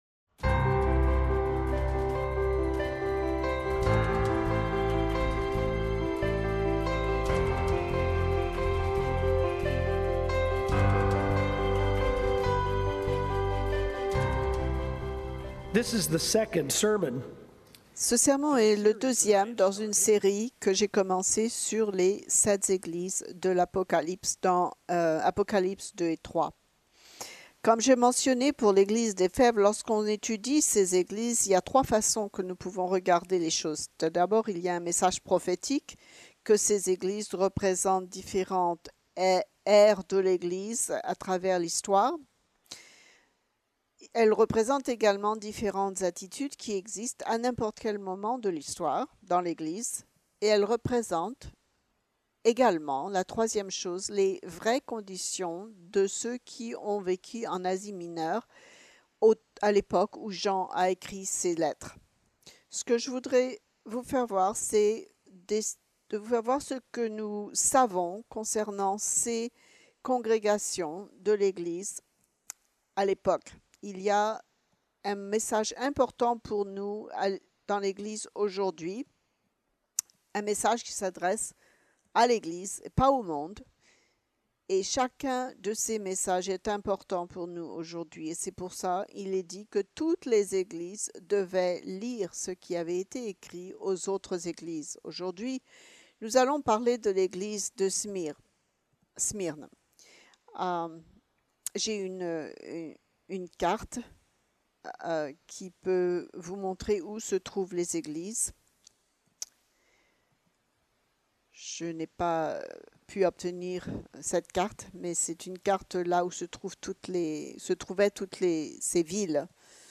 À Smyrne : Endure la persécution (avec traduction simultanée)
Ils ont été persécutés et Jésus leur dit de s’attendre à l’être encore davantage. Grâce à ce que l’histoire de cette ville nous révèle, et au message que Jésus leur a adressé, ce sermon nous offre une leçon spirituelle pour nous aujourd’hui, concernant la persécution qui peut avoir lieu lorsqu’on suit Dieu.